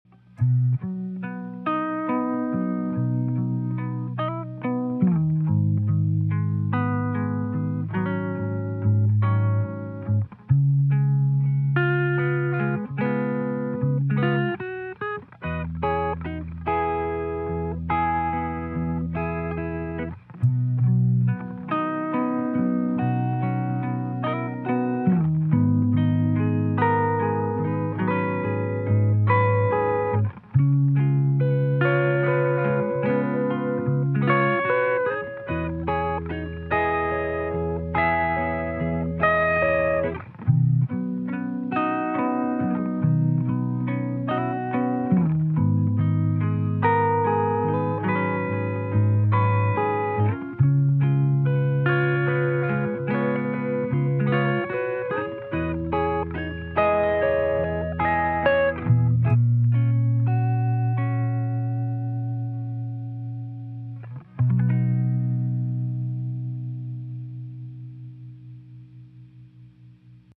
1961 Fender Bandmaster + Chroma